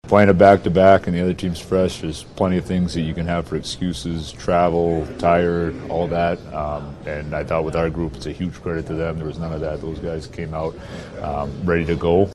Coach Dan Muse says the Penguins were firing from the opening faceoff.